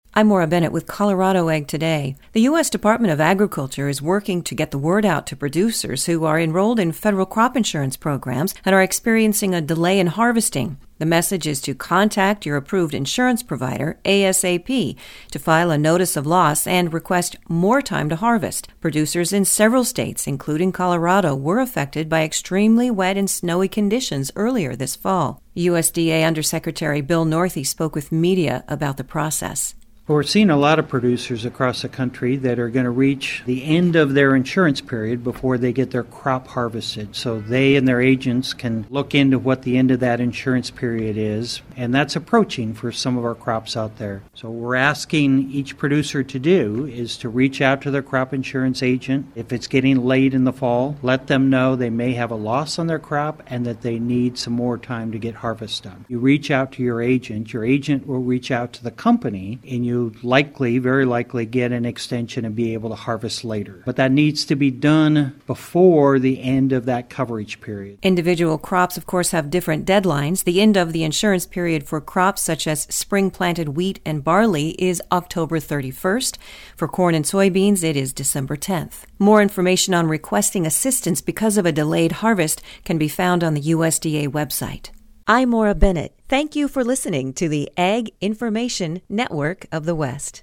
USDA Undersecretary Bill Northey spoke with media about the process.